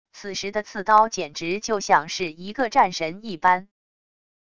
此时的刺刀简直就像是一个战神一般wav音频生成系统WAV Audio Player